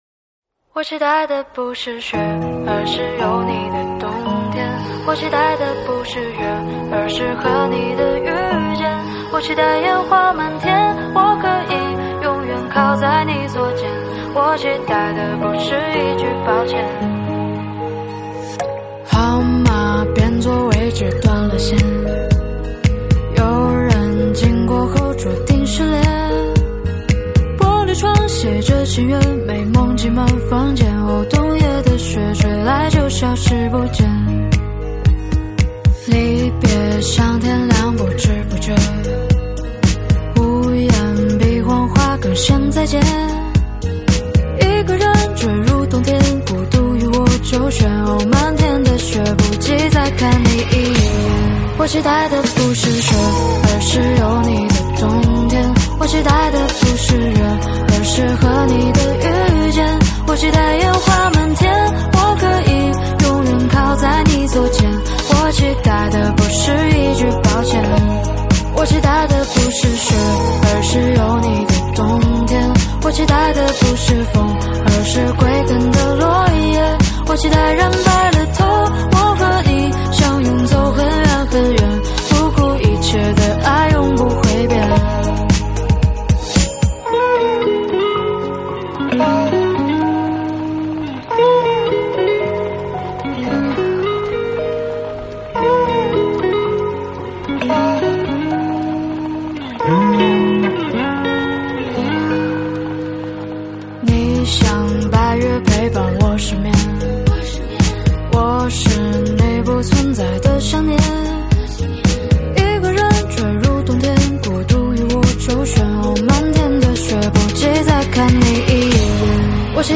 舞曲类别：现场串烧